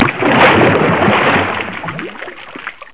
SPLASH1.mp3